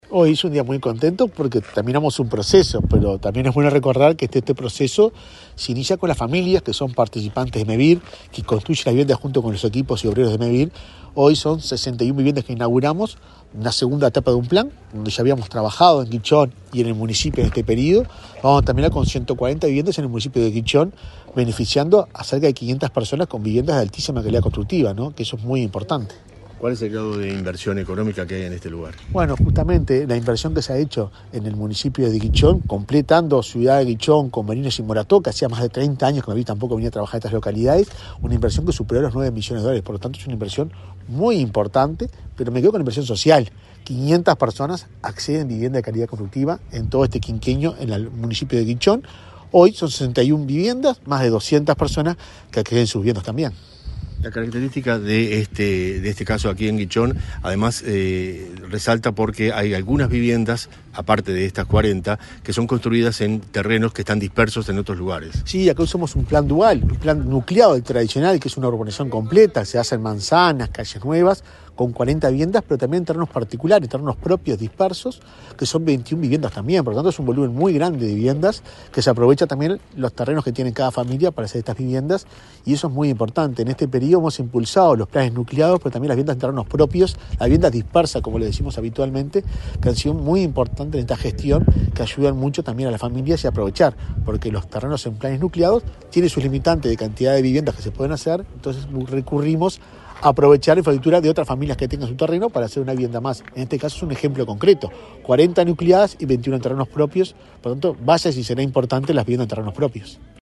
Declaraciones del presidente de Mevir, Juan Pablo Delgado 14/08/2024 Compartir Facebook X Copiar enlace WhatsApp LinkedIn Este miércoles 14, el presidente de Mevir, Juan Pablo Delgado, dialogó con la prensa, antes de inaugurar un plan nucleado de viviendas, en la localidad de Guichón, departamento de Paysandú.